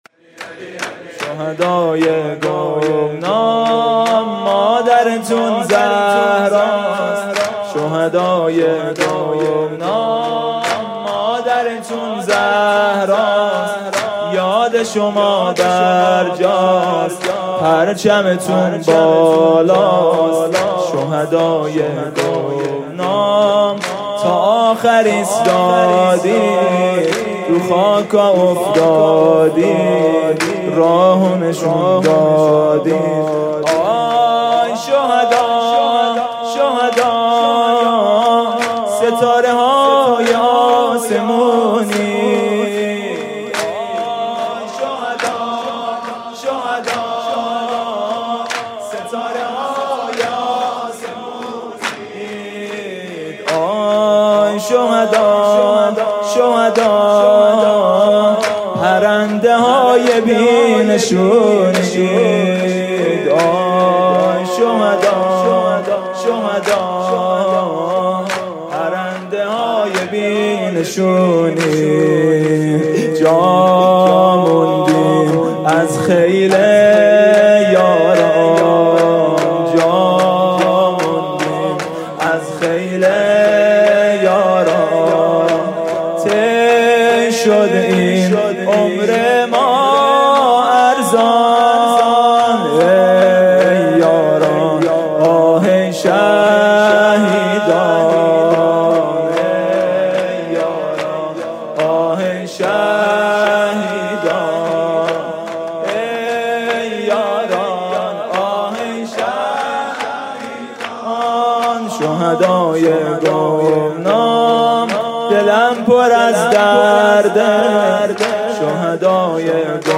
شب پنجم فاطمیه اول ۱۴۰۴ | هیأت میثاق با شهدا
music-icon زمزمه: اسما دیدی که آخرش، موندم با اشکای حسن...